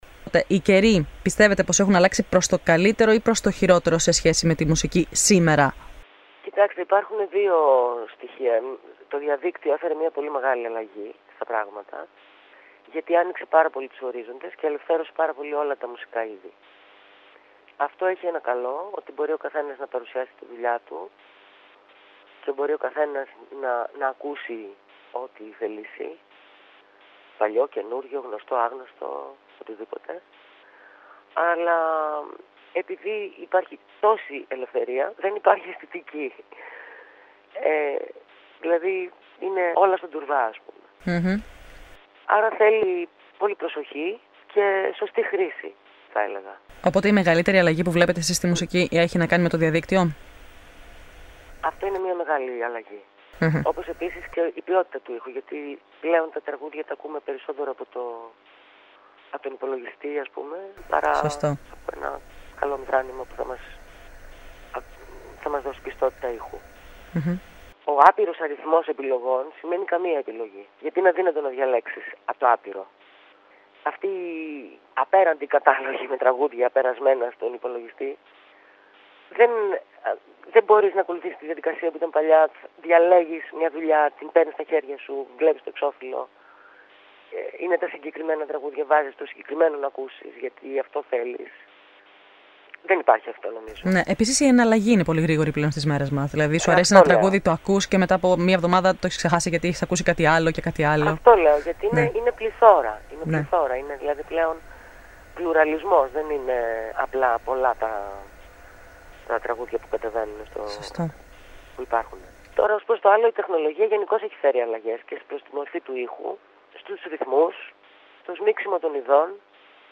Listen to her talking about the situation in Greece today (2014) in arts and politics. This is the second part of the interview (audio in Greek language).